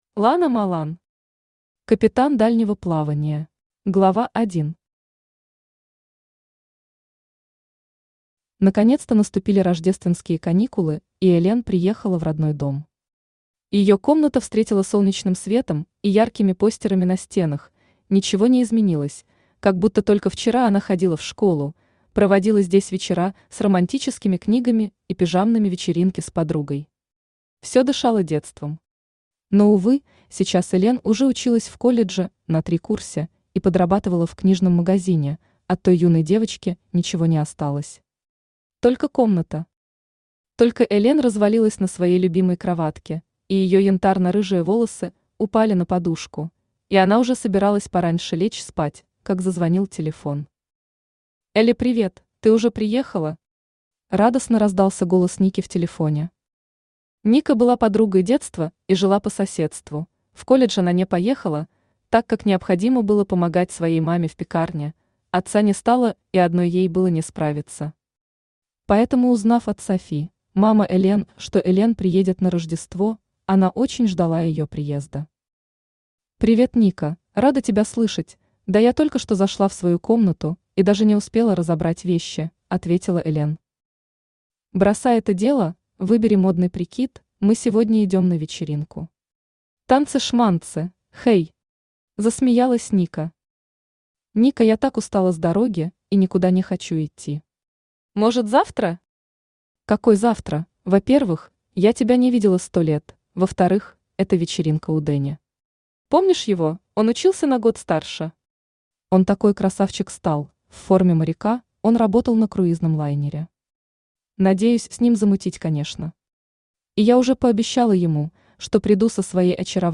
Аудиокнига Капитан дальнего плавания | Библиотека аудиокниг
Aудиокнига Капитан дальнего плавания Автор Lana Smolan Читает аудиокнигу Авточтец ЛитРес.